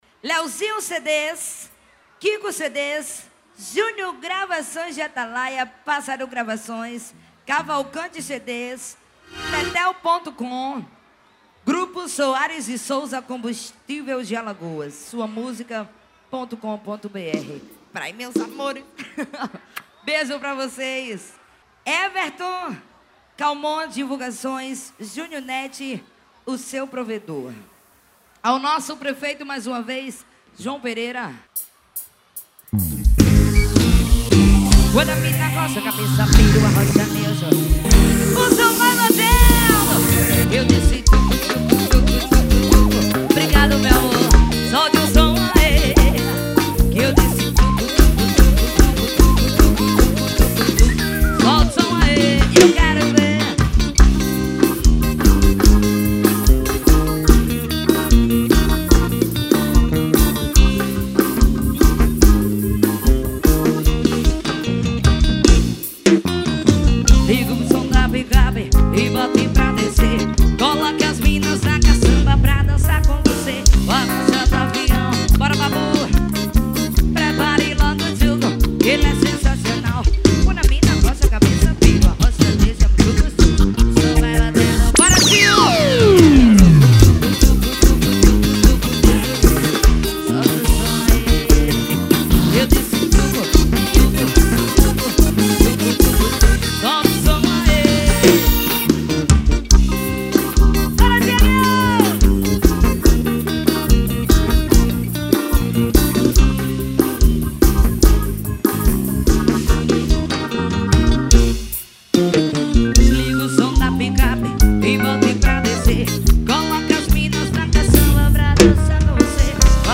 Forró de paredão